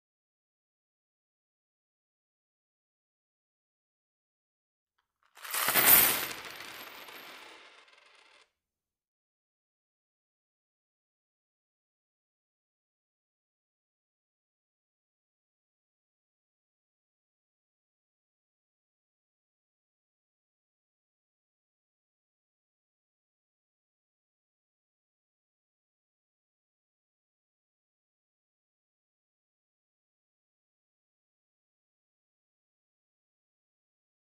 Mapa-06-Comercio-Coins-on-a-wood-table.mp3